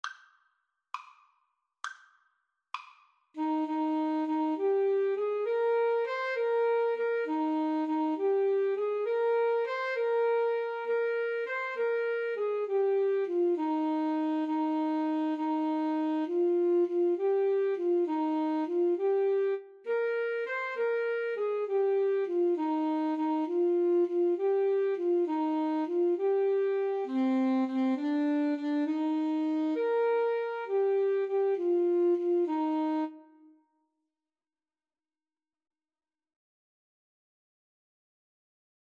Alto SaxophoneTenor Saxophone
6/8 (View more 6/8 Music)
Classical (View more Classical Alto-Tenor-Sax Duet Music)